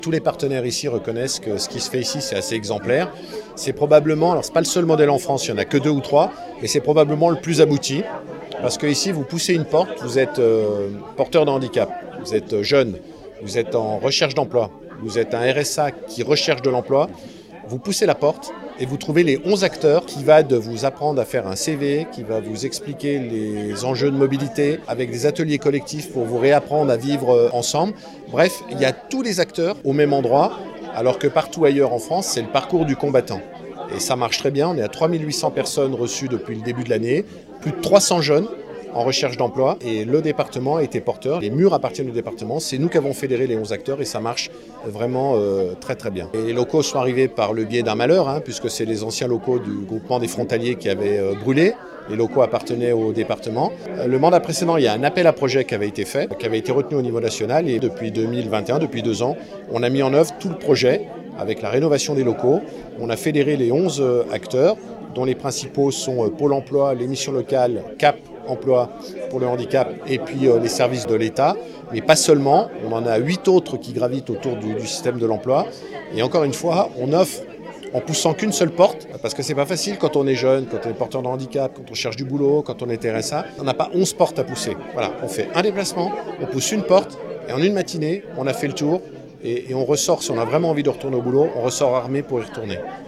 Annemasse : inauguration de la Maison de l'Insertion et de l'Emploi (interview)
Martial Saddier, président du Département de la Haute-Savoie, au micro La Radio Plus
martial-saddier-inauguration-mei-annemasse-a-diffuser-5434.mp3